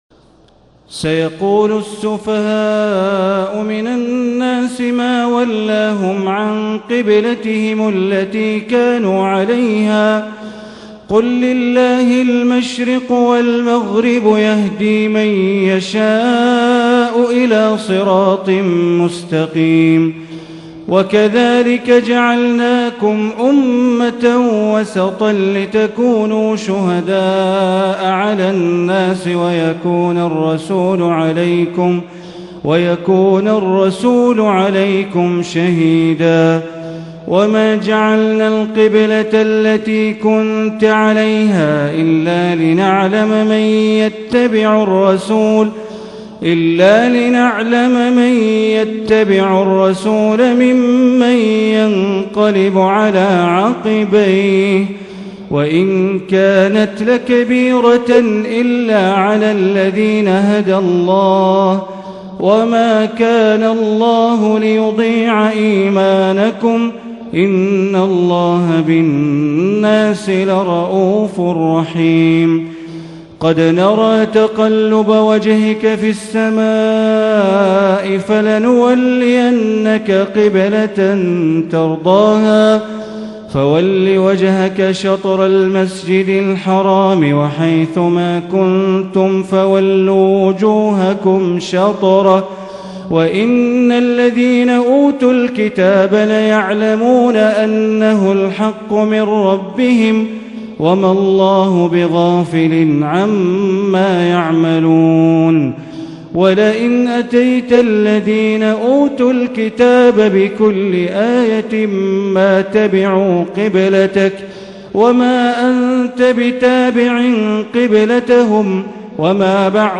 تهجد ليلة 22 رمضان 1440هـ من سورة البقرة (142-218) Tahajjud 22 st night Ramadan 1440H from Surah Al-Baqara > تراويح الحرم المكي عام 1440 🕋 > التراويح - تلاوات الحرمين